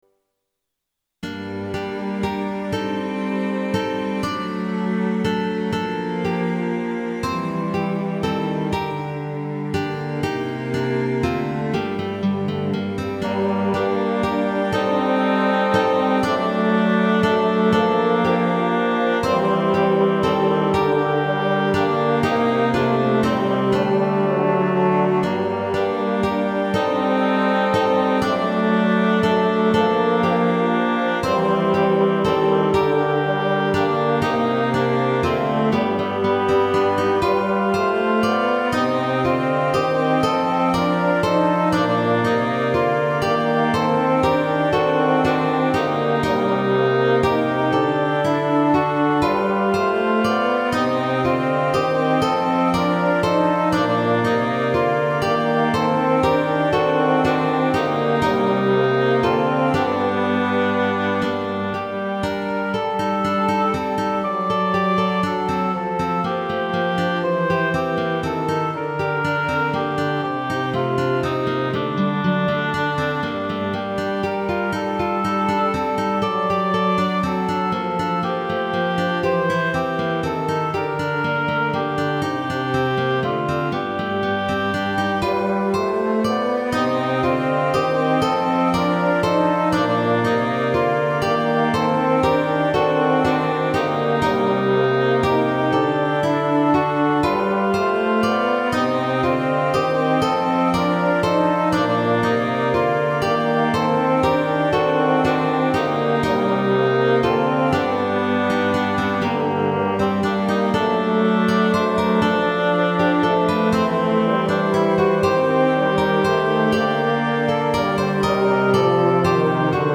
▼DL↓   1.0 フルート オーボエ ホルン ファゴット ギター